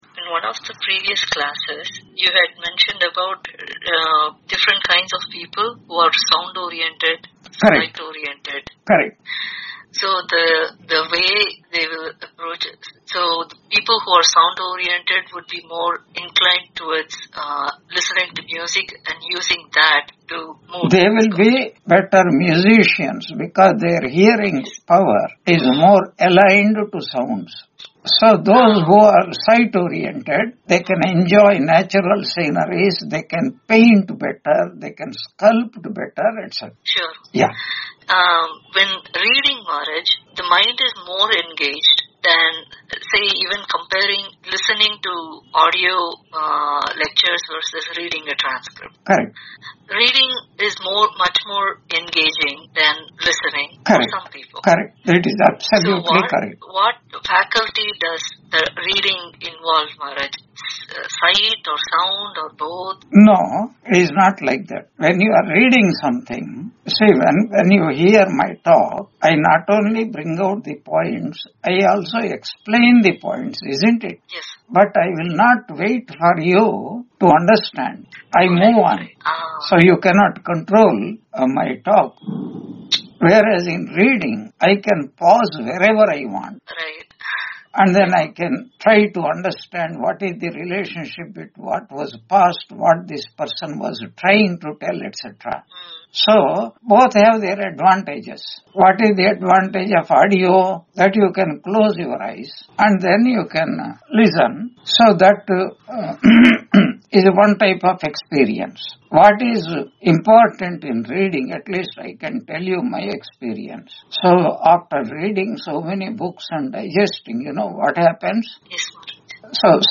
Chandogya Upanishad 3.12 Summary Lecture 154 on 09 November 2025 Q&A - Wiki Vedanta